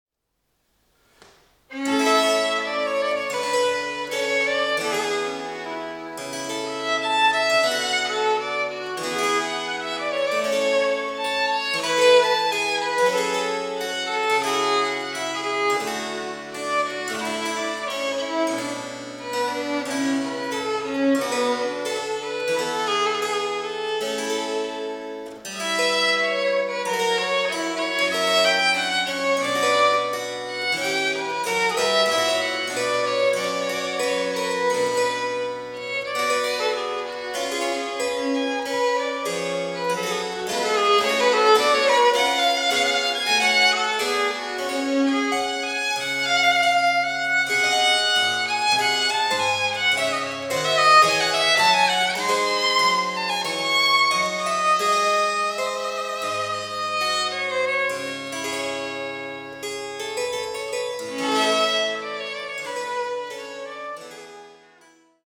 This year, I also participated in the Bachfest as performer in one of the free concerts in the Sommersaal on 6 May at 3 pm, playing
Baroque Violin